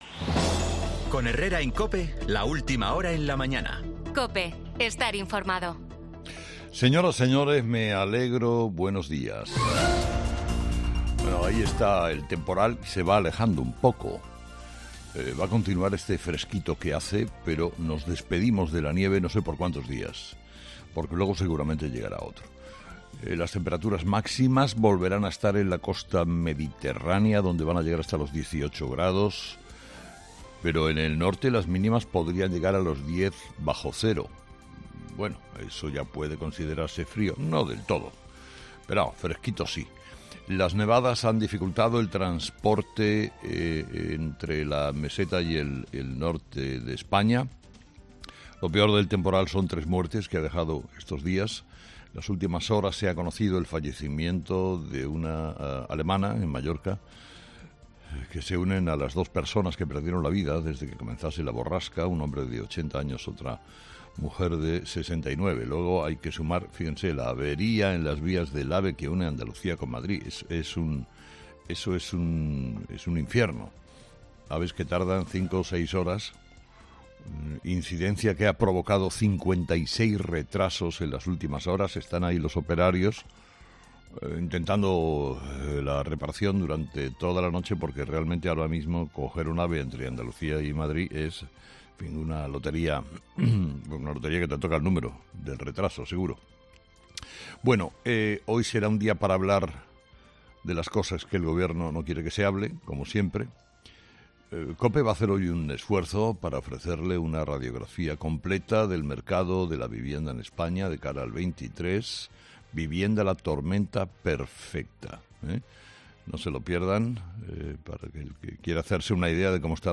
Carlos Herrera, director y presentador de 'Herrera en COPE', ha comenzado el programa de este martes analizando las principales claves de la jornada, que pasan, entre otros asuntos, por el Informe COPE: 'Vivienda, la tormenta perfecta', en el que analizamos la evolución de los precios de venta y alquiler desde antes la pandemia y avanzamos qué nos depara el 2023 en materia inmobiliaria.